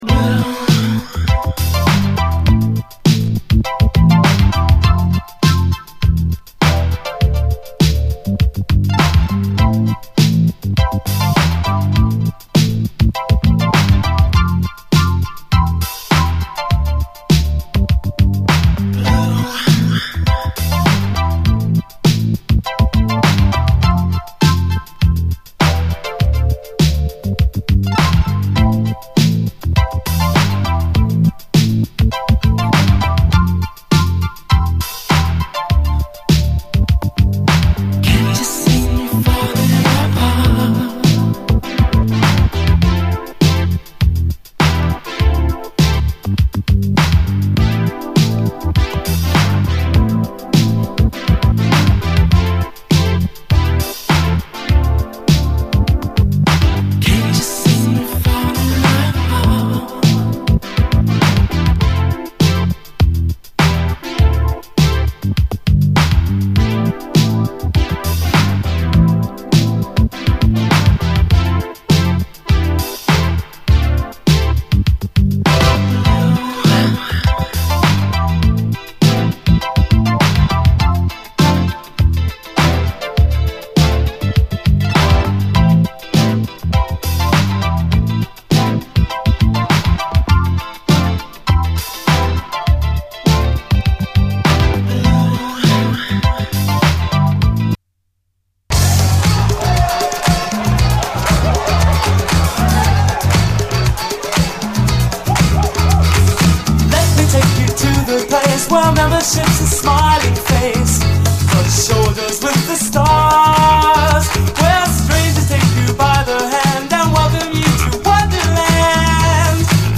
DISCO, 80's～ ROCK, ROCK, FUNK-A-LATINA, 7INCH